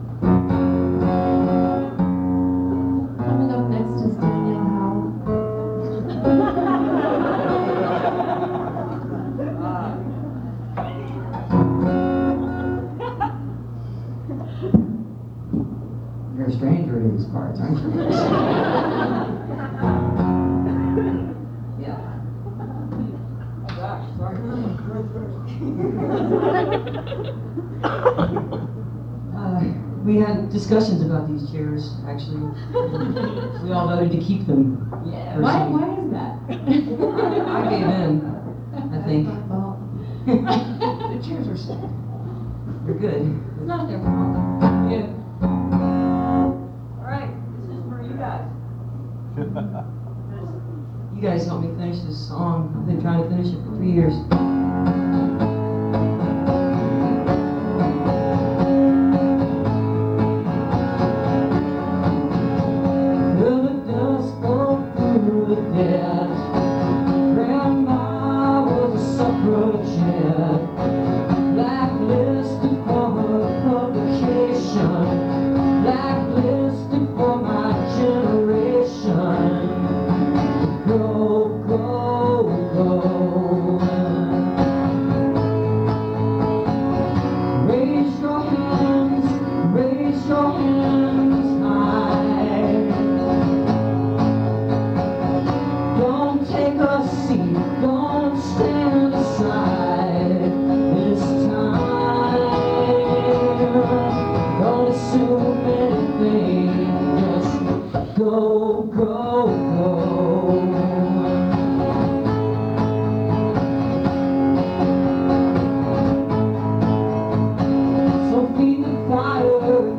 (songwriters in the round)
(first set)